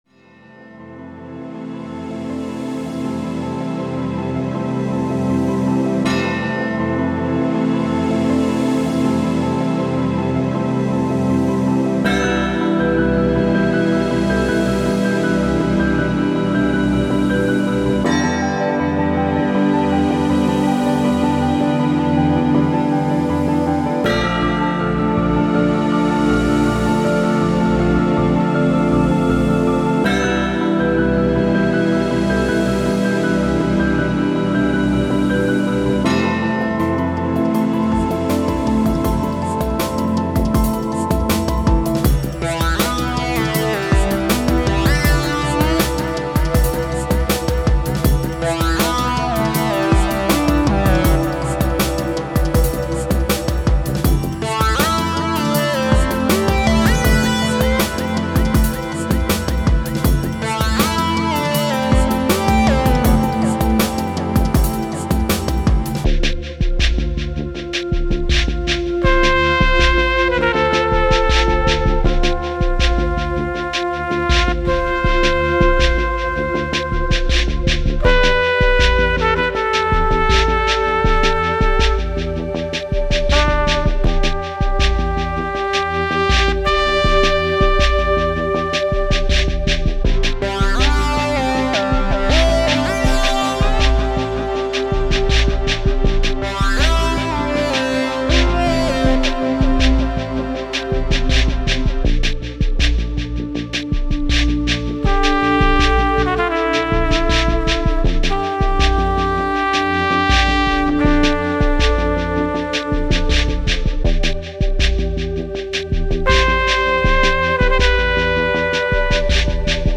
με chillout διάθεση